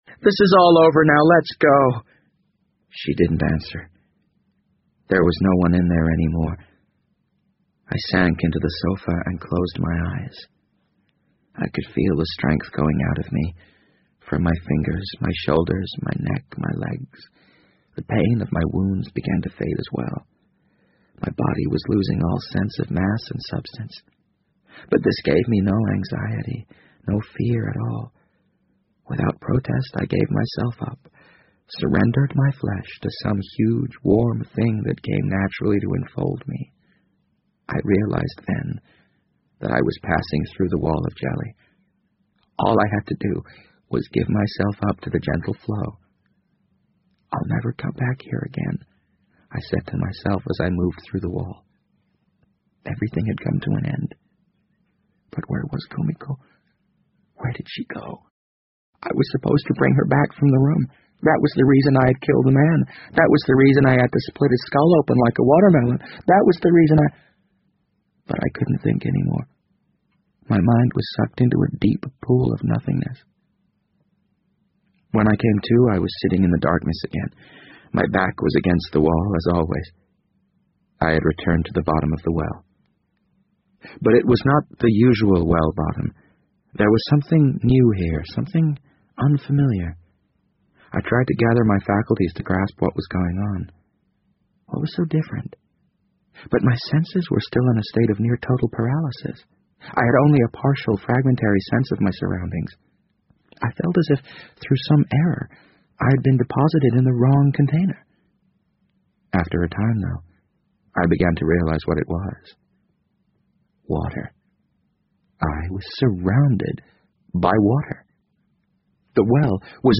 BBC英文广播剧在线听 The Wind Up Bird 015 - 11 听力文件下载—在线英语听力室